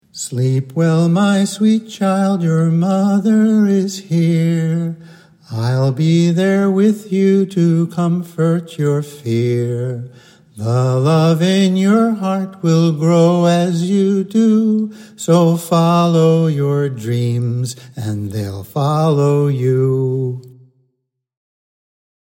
Alondrias-lullaby-GB-vocal.mp3